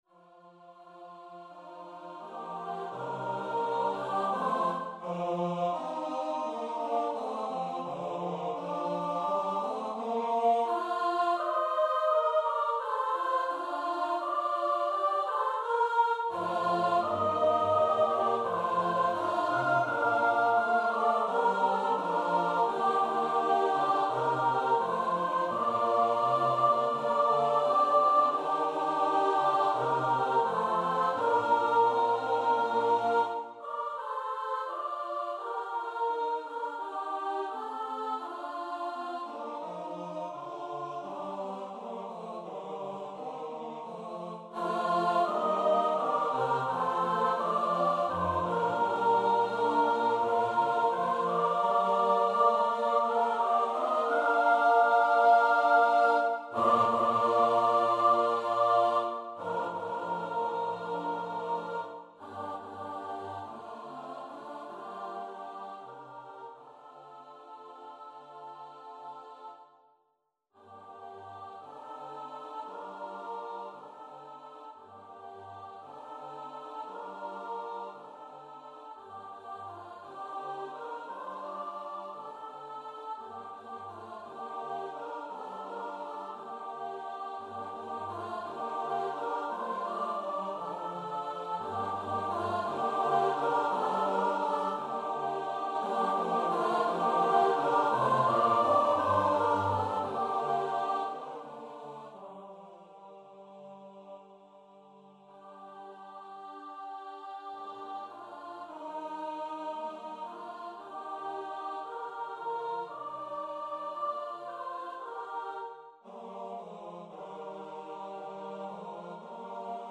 a cappella SATB choir